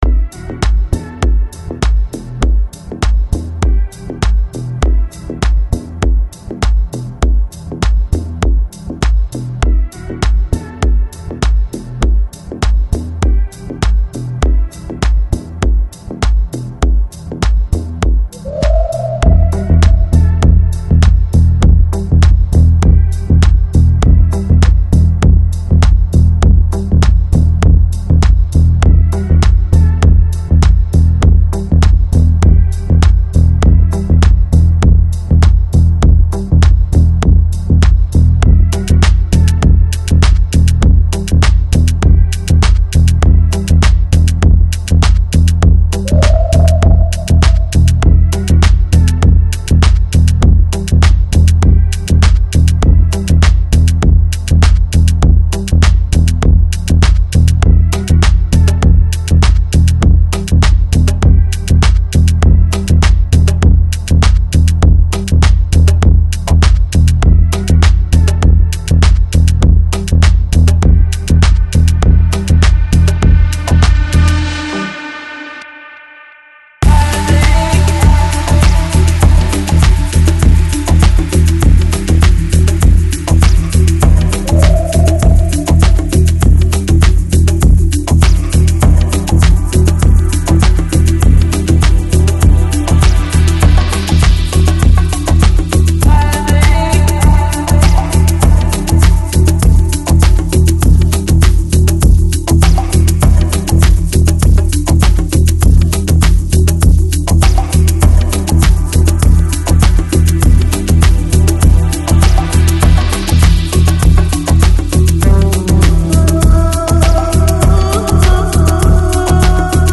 Жанр: Deep House, Organic House